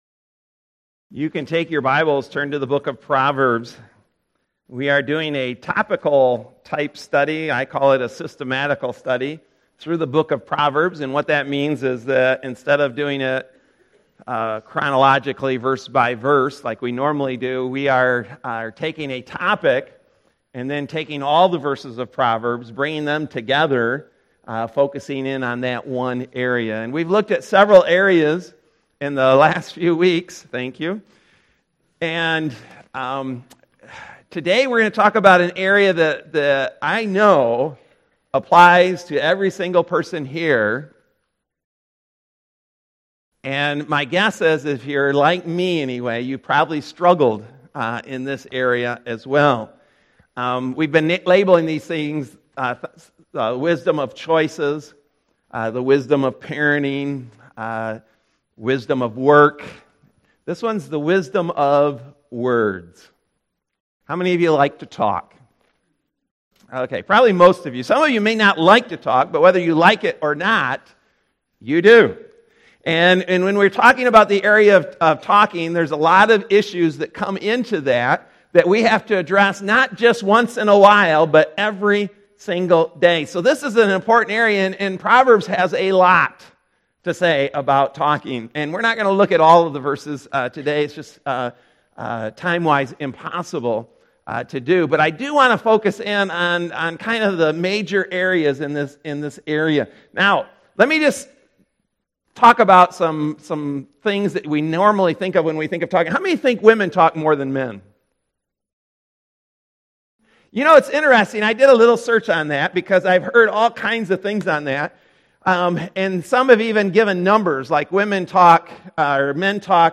Mar 17, 2019 Wisdom That Works: The Wisdom Of Words MP3 SUBSCRIBE on iTunes(Podcast) Notes Discussion Sermons in this Series March 17, 2019 Loading Discusson...